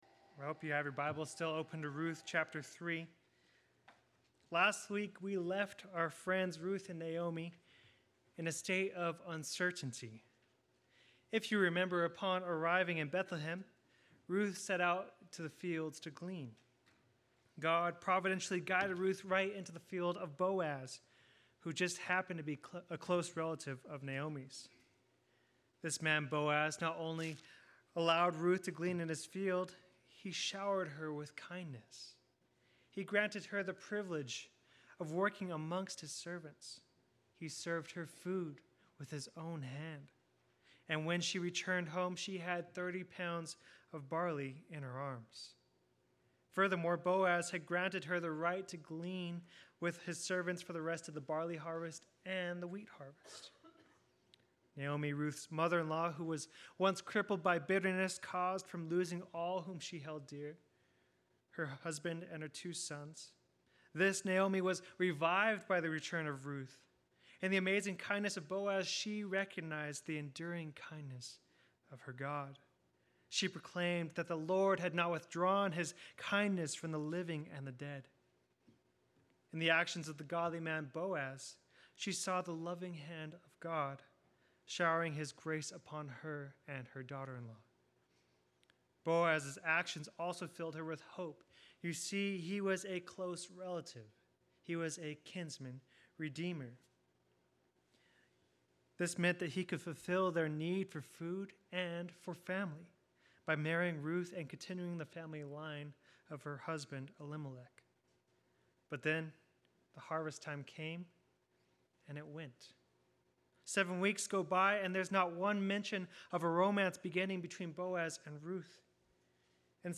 Play Sermon Get HCF Teaching Automatically.
The Midnight Proposal Sunday Worship